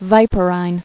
[vi·per·ine]